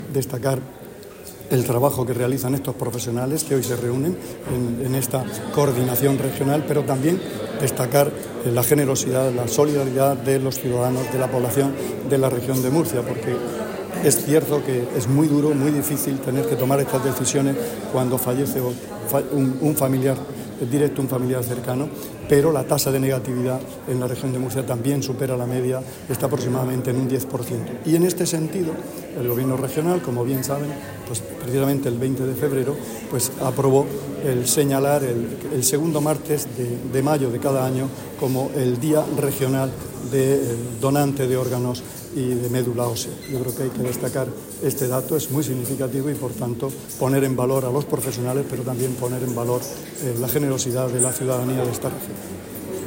Sonido/ Declaraciones del consejero de Salud sobre la donación y el trasplante de órganos en la Región de Murcia [mp3].